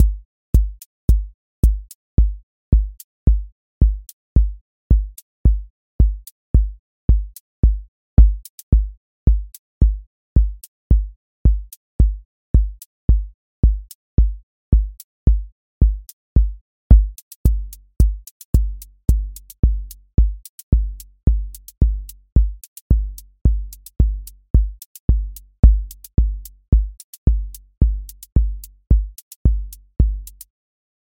Molten Hour QA Listening Test house Template: four_on_floor April 18, 2026 ← Back to all listening tests Audio Molten Hour Open MP3 directly Selected Components macro_house_four_on_floor voice_kick_808 voice_hat_rimshot voice_sub_pulse Test Notes What This Test Is Molten Hour Selected Components macro_house_four_on_floor voice_kick_808 voice_hat_rimshot voice_sub_pulse